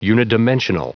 Prononciation du mot unidimensional en anglais (fichier audio)
Prononciation du mot : unidimensional